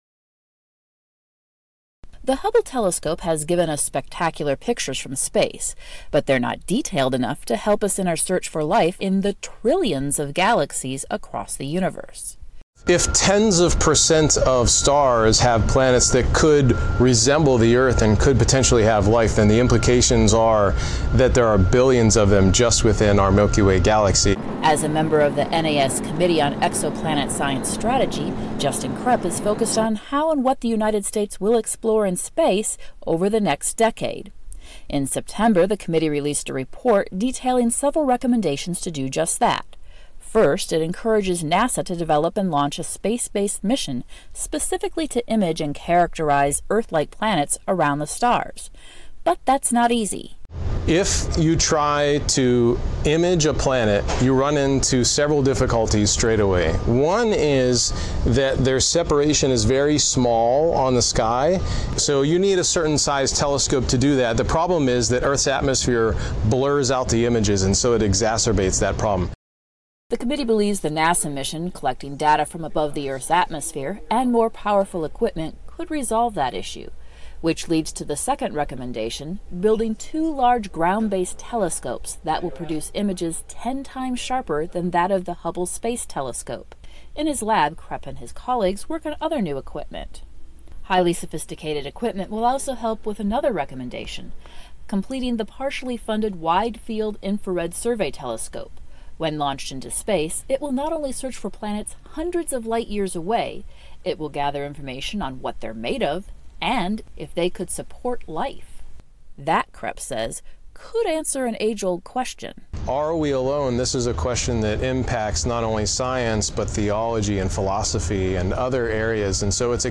·On-line English TV ·English publication ·broadcasting station ·Classical movie ·Primary English study ·English grammar ·Commercial English ·Pronunciation ·Words ·Profession English ·Crazy English ·New concept English ·Profession English ·Free translation ·VOA News ·BBC World News ·CNN News ·CRI News ·English Songs ·English Movie ·English magazine